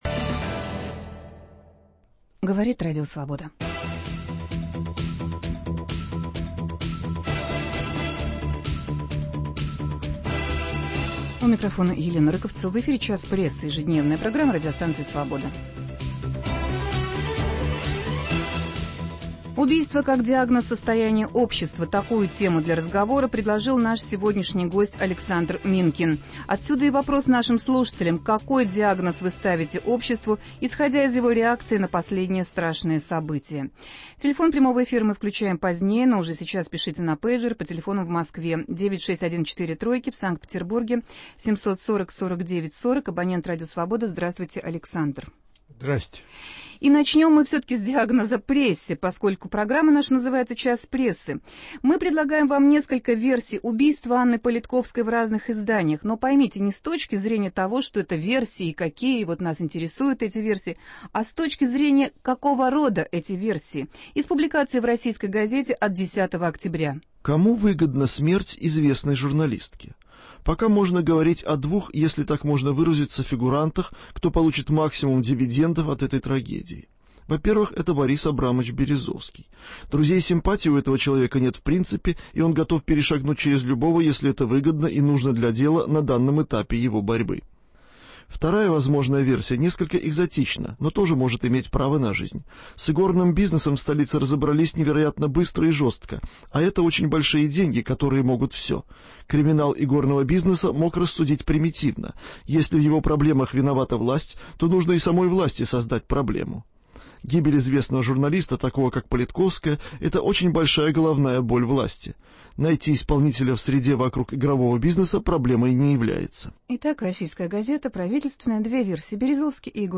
Убийство как диагноз состояния общества. Гость студии - обозреватель газеты "Московский комсомолец" Александр Минкин.